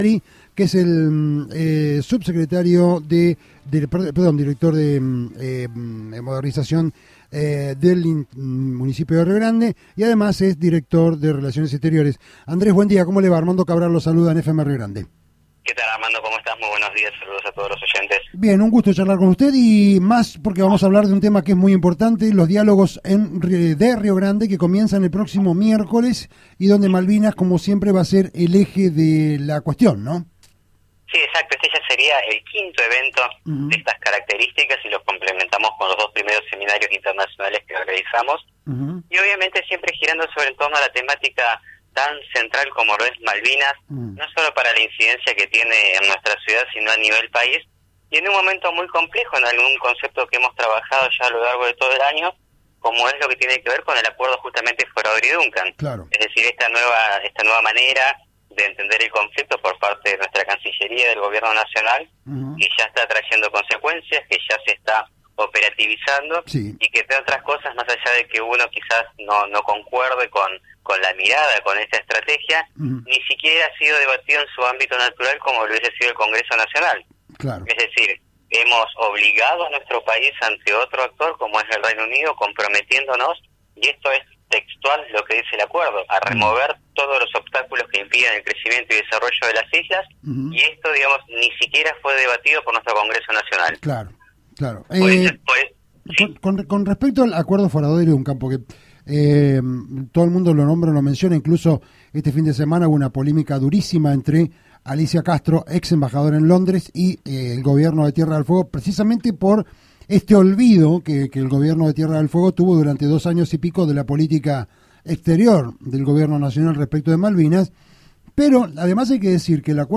En diálogo con este medio el funcionario explicó la gravedad de la política exterior del gobierno nacional en la negociación por Malvinas, y lo que significa en recursos naturales la entrega de 3 millones de kilómetros cuadrados a Gran Bretaña y como se intentó hacer pasar este acuerdo como un simple comunicado hasta que la premier británica Theresa